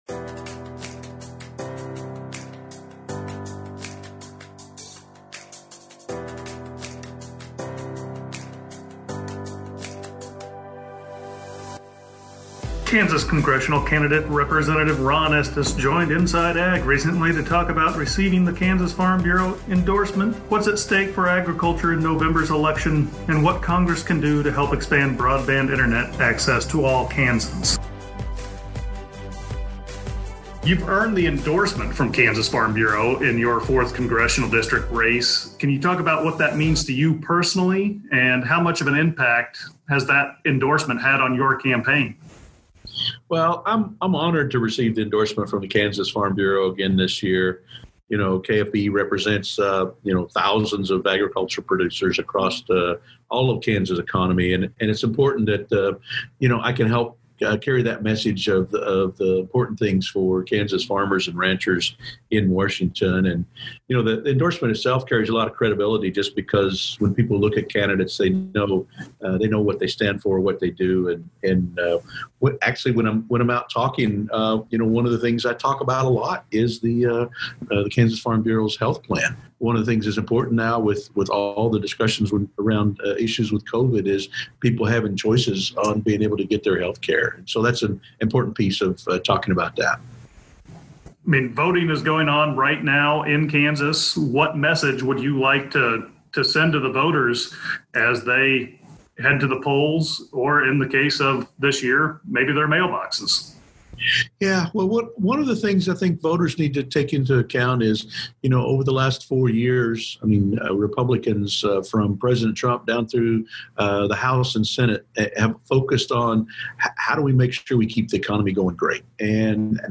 Chatting with Rep. Ron Estes
talks with Rep. Ron Estes who's running for re-election. Topics include what it means to receive KFB's endorsement, what's at stake in this year's election and what Congress can do to expand broadband access.